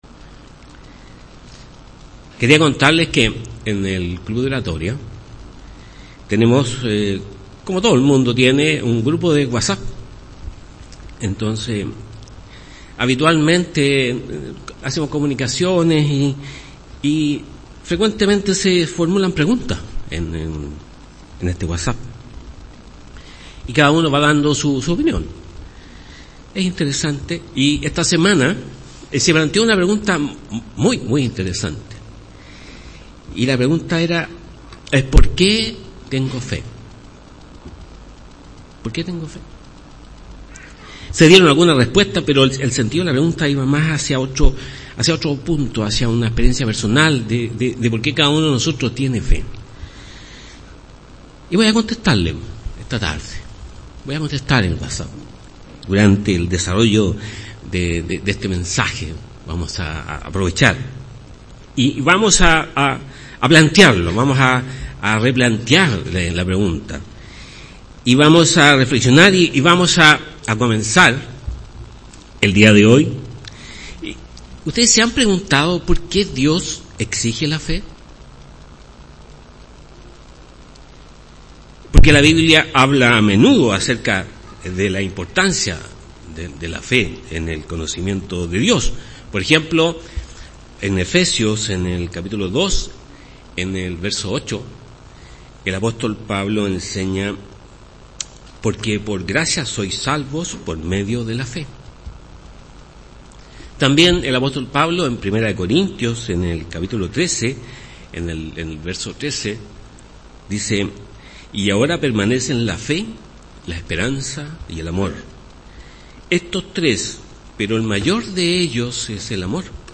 Mensaje entregado el 17 de marzo de 2018.
Sermones